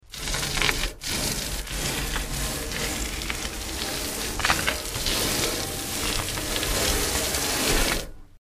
Sand Bag; Dragged, Scrape On Metal Deck.